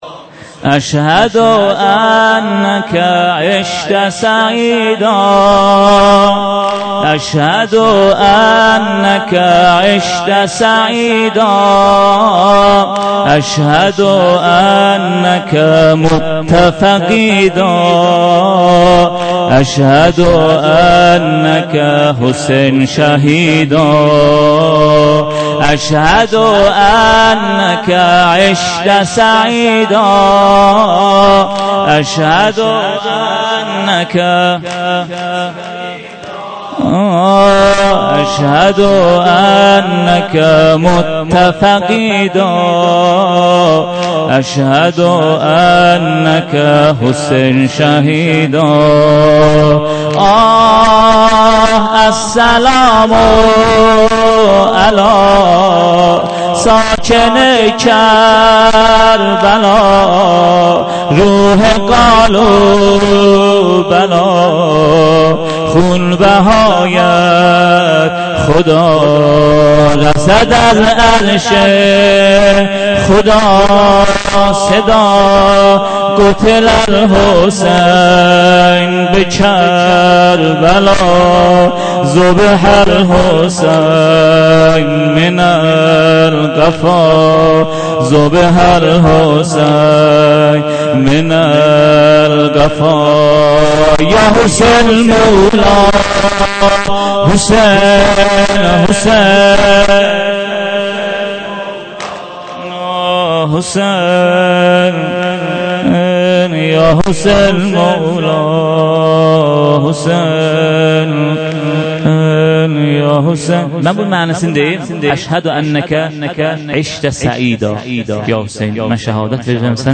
محرم 97 - شب اول - بخش سوم سینه زنی